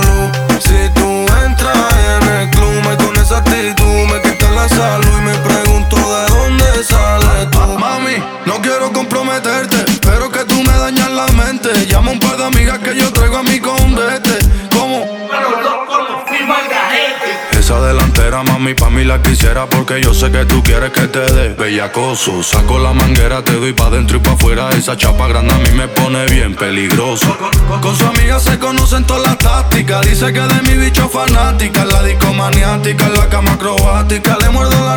Жанр: Латино
Urbano latino